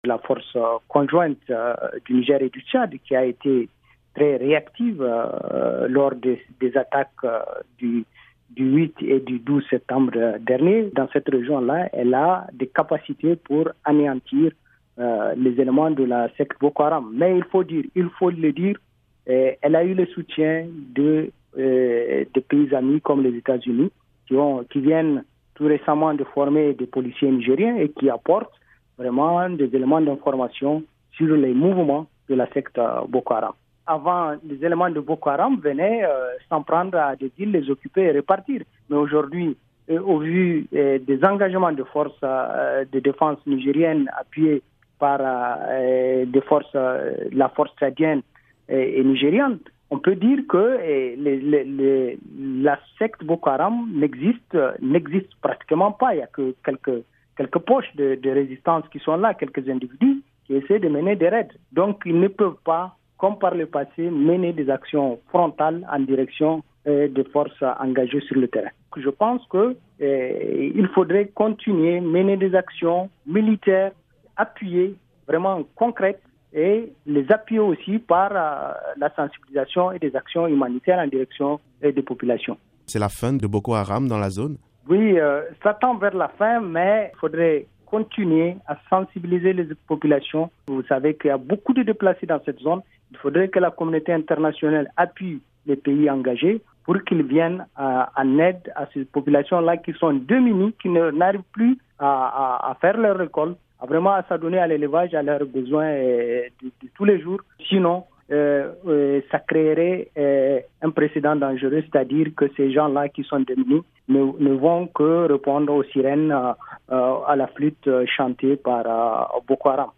spécialiste des questions de sécurité au sahel joint par